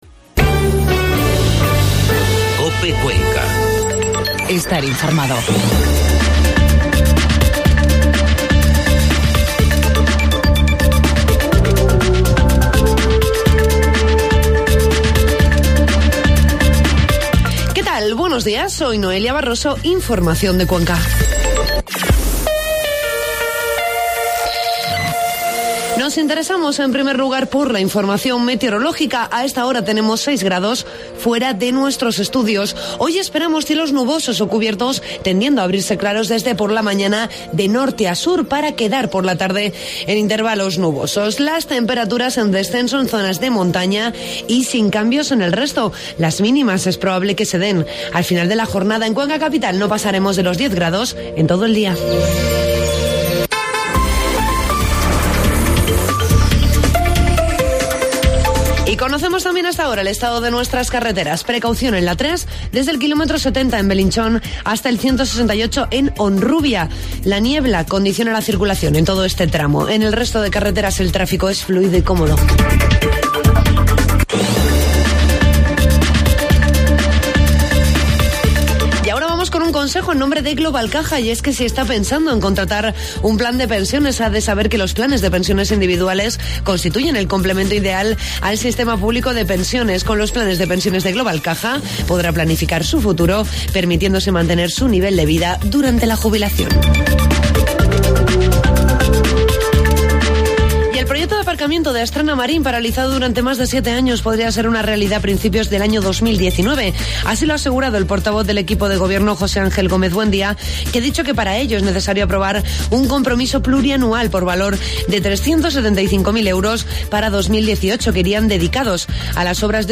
Informativo matinal COPE Cuenca 29 de noviembre